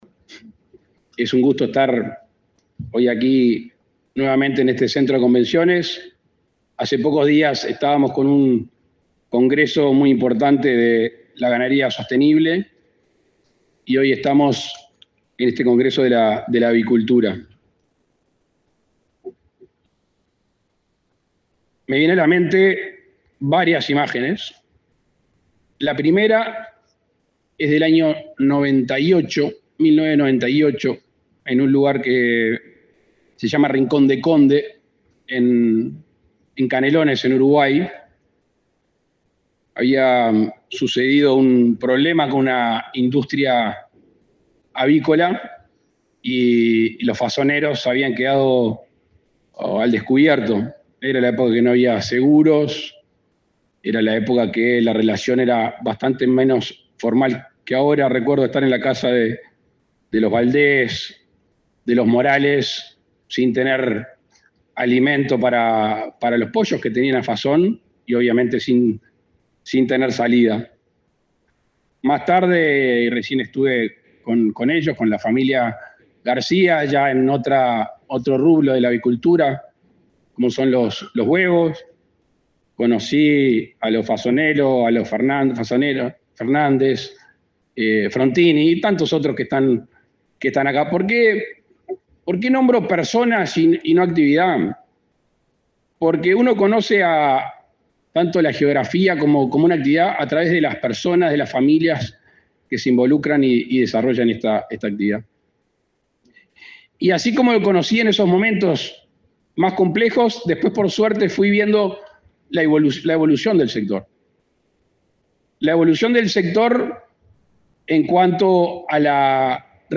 Palabras del presidente Luis Lacalle Pou
El presidente Luis Lacalle Pou participó, este jueves 14, del 28.° Congreso Latinoamericano de Avicultura, que se realiza en el Centro de Convenciones